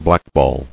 [black·ball]